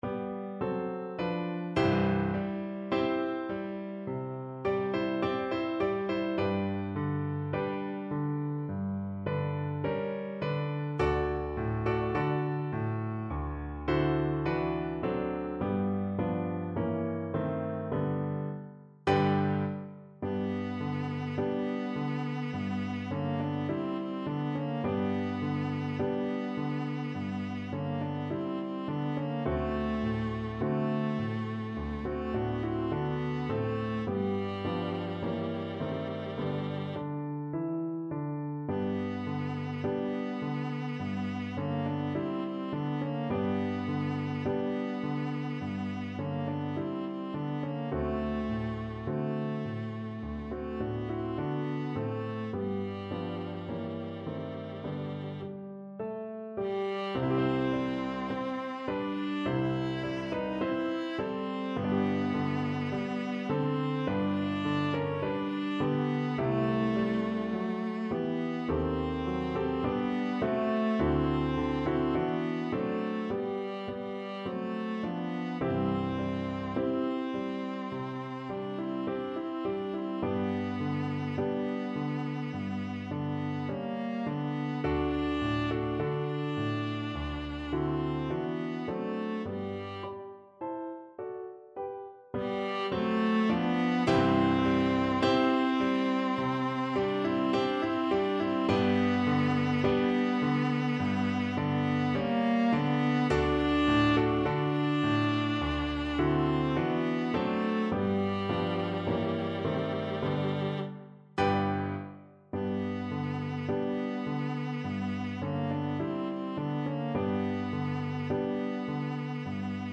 Andantino =c.52 (View more music marked Andantino)
2/4 (View more 2/4 Music)
Classical (View more Classical Viola Music)